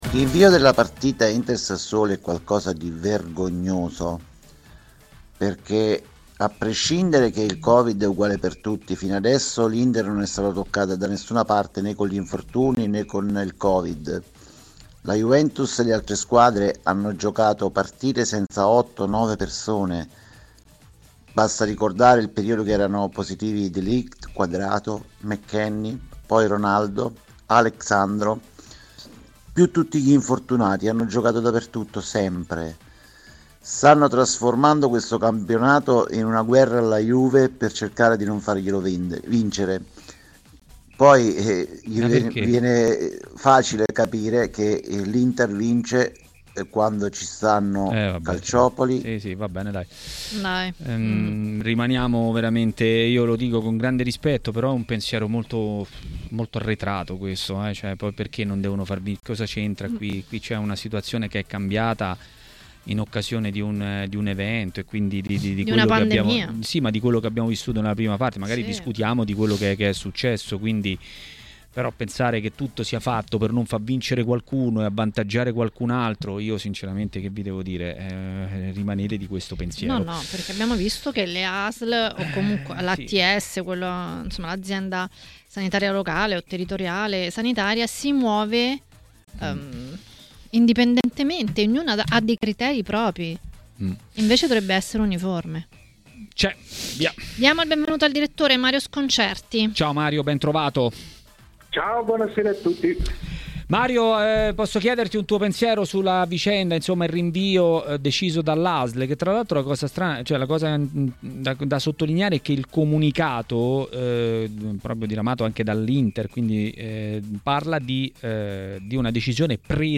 TMW Radio Regia Ascolta l'audio Ospiti: Mario Sconcerti